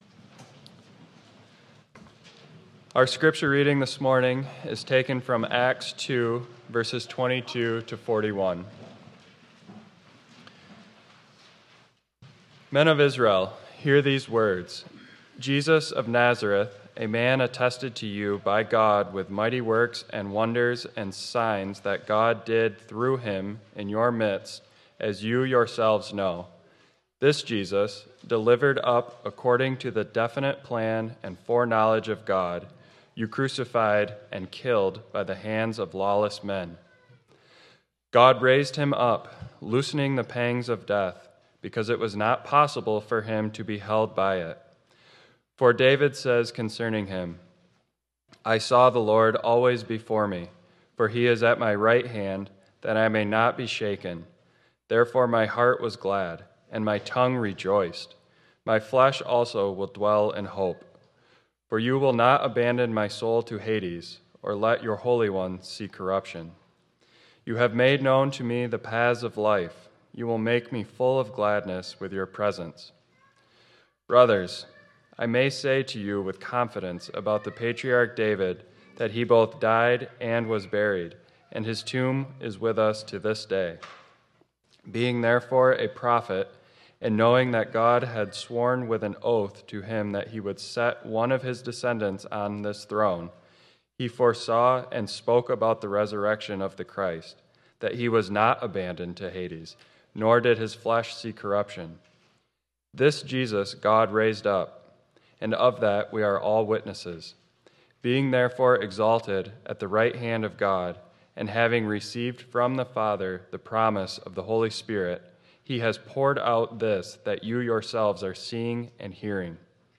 2.15.26 Sermon.m4a